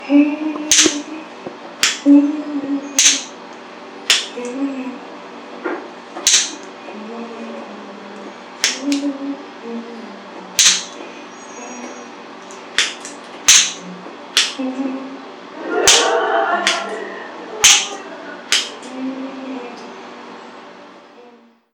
별꼴_허밍.mp3